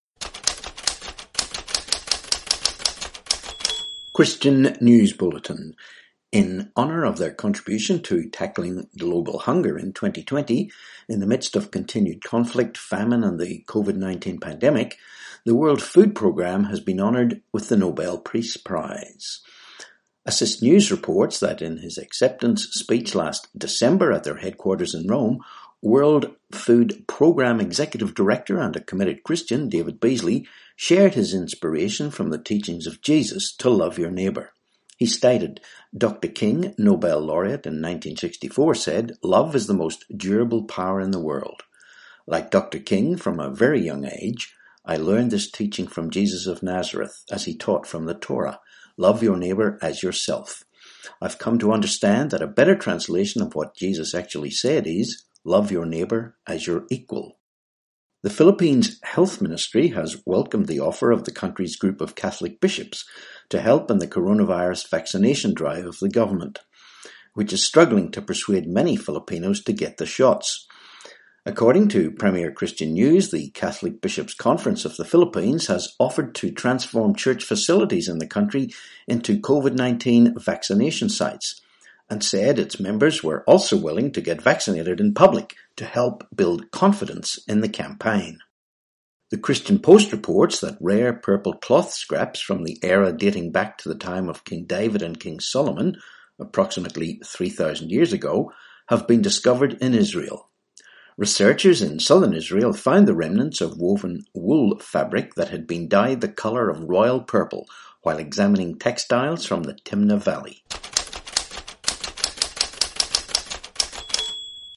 7Feb21 Christian News Bulletin